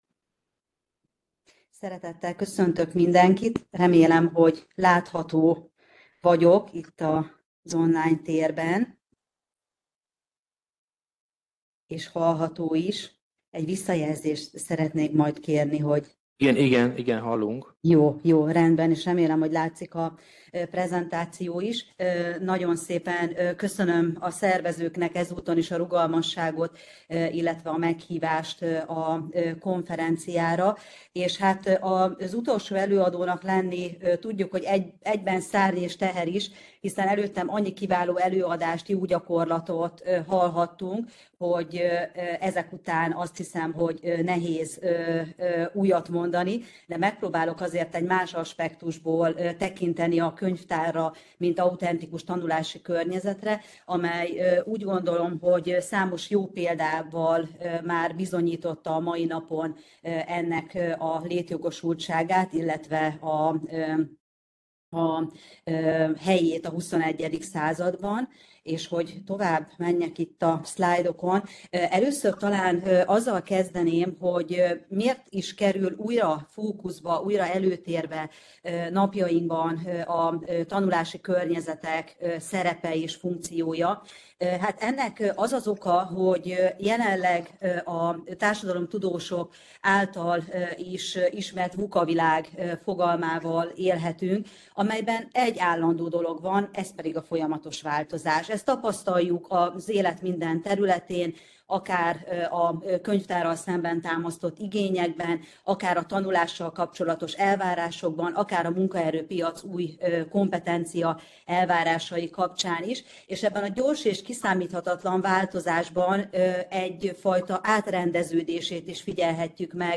Elhangzott a Központi Statisztikai Hivatal Könyvtár és a Magyar Könyvtárosok Egyesülete Társadalomtudományi Szekciója Szakkönyvtári seregszemle 2025 című
lecturer